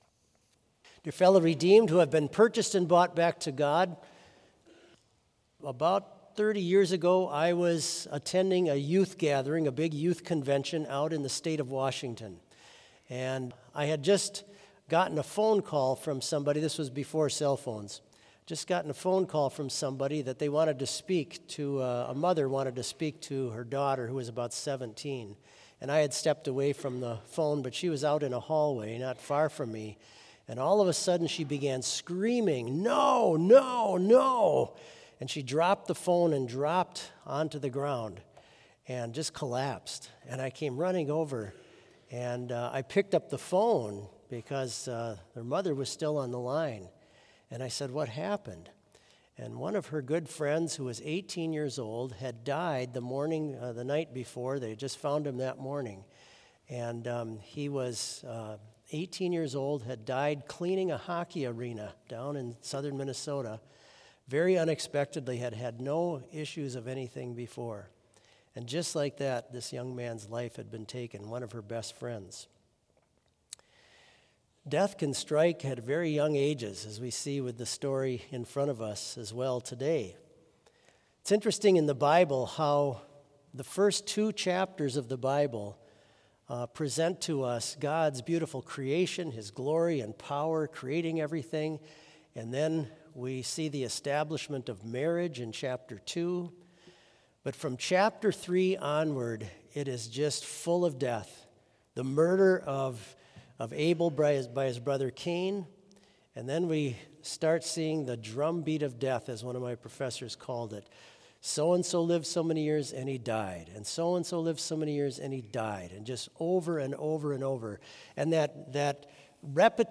Complete Service
• Hymn 406 - Lord, Thee I Love With All My Heart View
• Devotion
This Chapel Service was held in Trinity Chapel at Bethany Lutheran College on Wednesday, November 13, 2024, at 10 a.m. Page and hymn numbers are from the Evangelical Lutheran Hymnary.